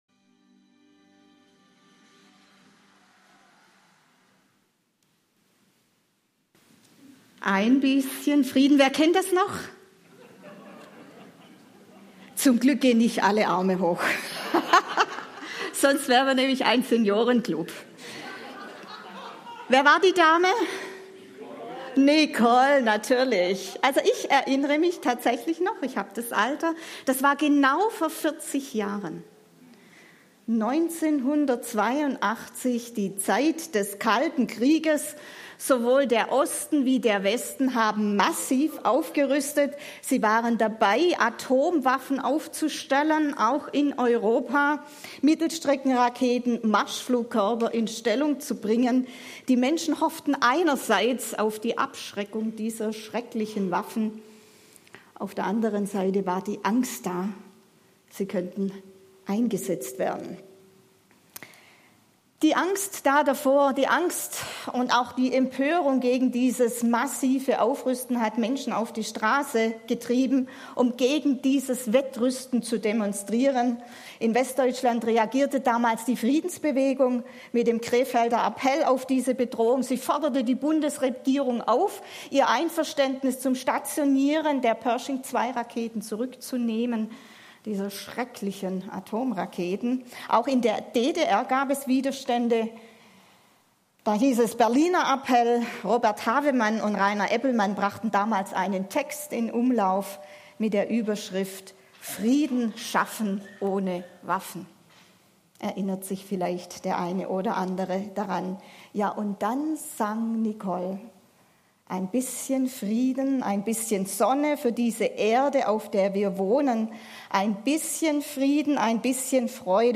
Passage: Philipper 4, 6-7 Dienstart: Gottesdienst